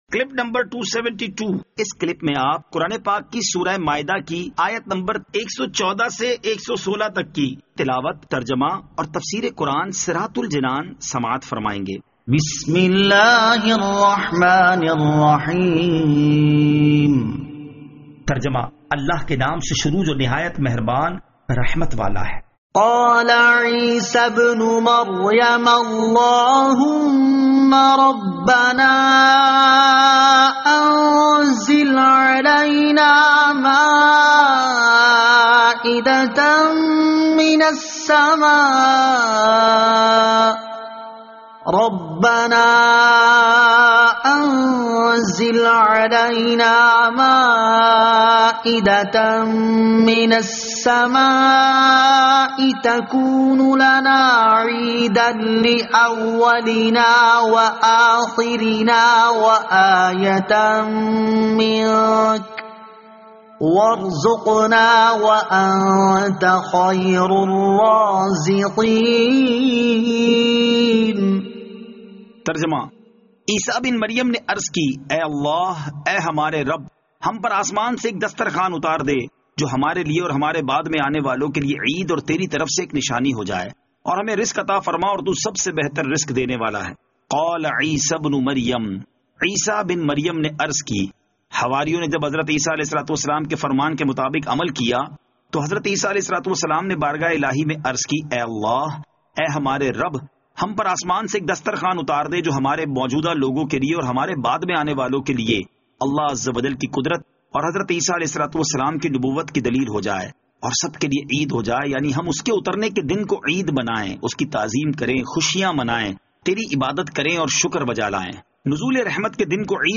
Surah Al-Maidah Ayat 114 To 116 Tilawat , Tarjama , Tafseer